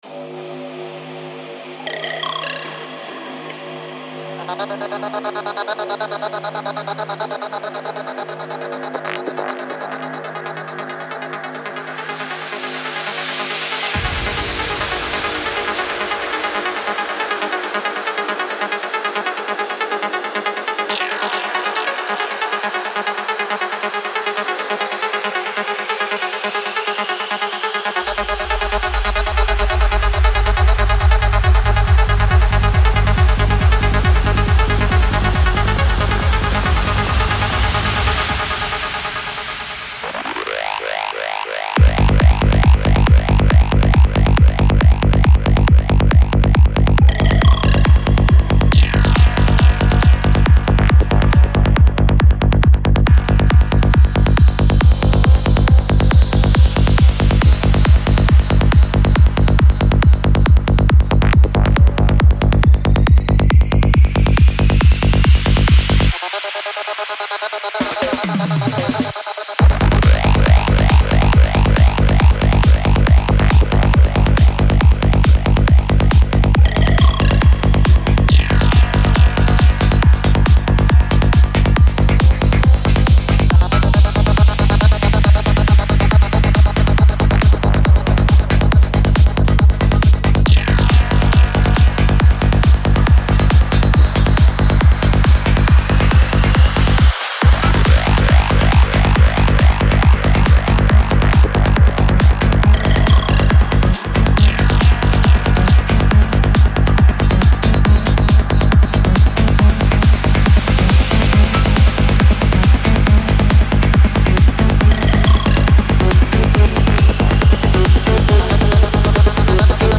ژانر : هارد سایکو تمپو : 138